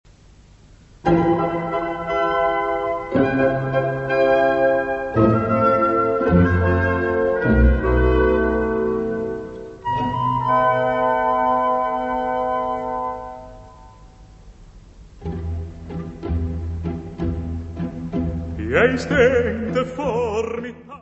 Peer Gynet, incidental music
: stereo; 12 cm + folheto
Área:  Música Clássica